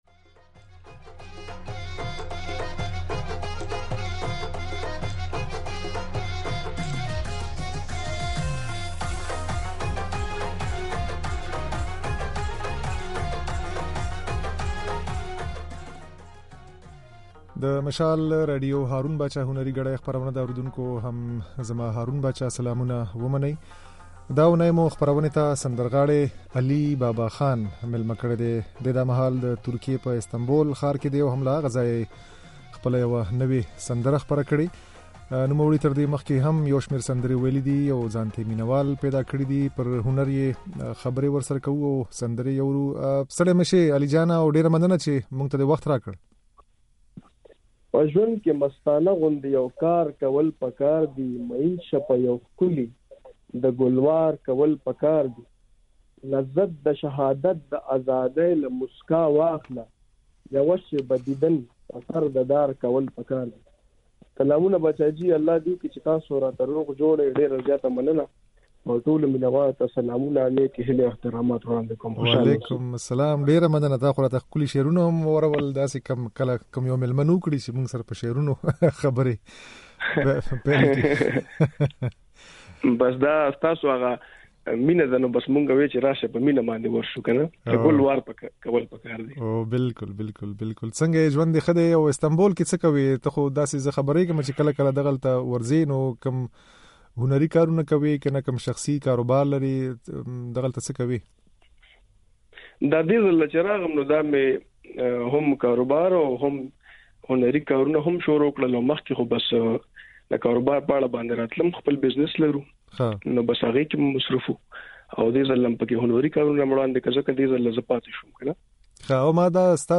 د نوموړي دا خبرې او د نوې يوې ترڅنګ هغه سندرې يې هم د غږ په ځای کې اورئ چې لږه موده مخکې يې ويلې دي.